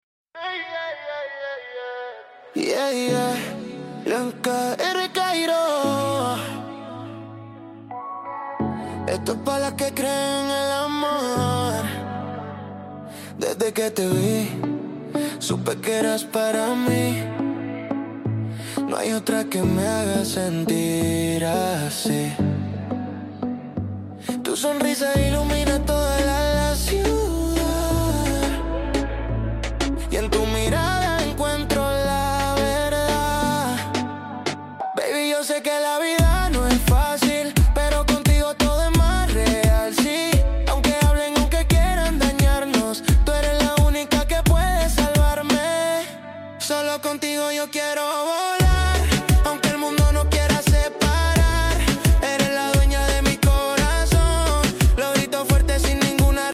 A fusion of energy, rhythm, and pure vibes you can’t miss.